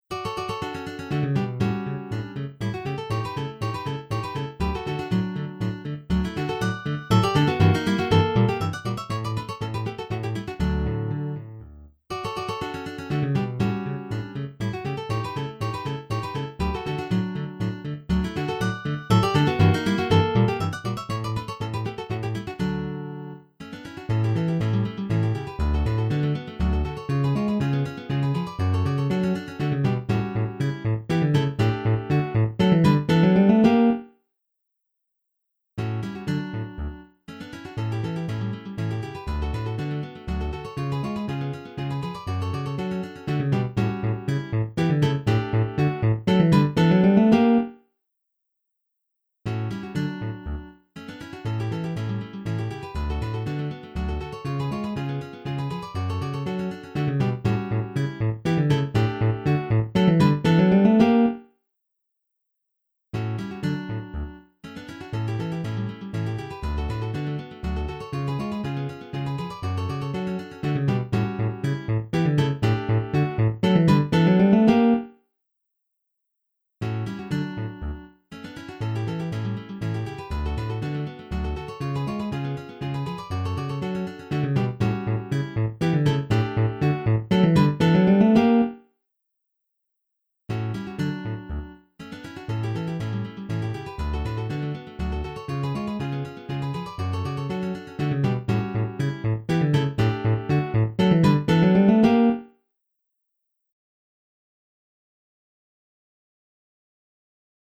arranged for Guitar Quartet